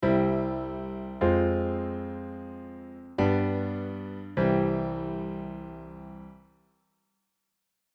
こ、これは何かちょっぴり悲しい感じがするよっ！
この２番目のコードが、ずばりサブドミナント・マイナーさんなのですっ。